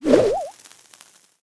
dynamike_throw_02.wav